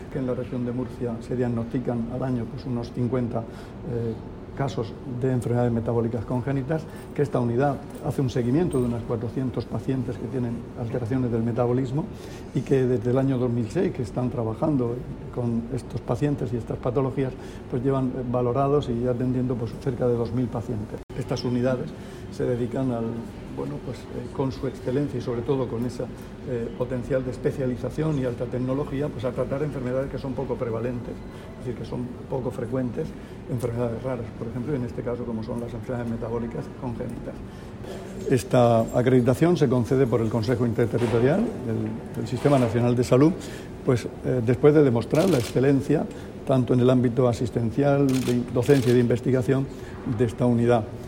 Declaraciones del consejero de Salud, Juan José Pedreño, sobre el nuevo Centro de Referencia Nacional (CSUR) de enfermedades metabólicas. [mp3]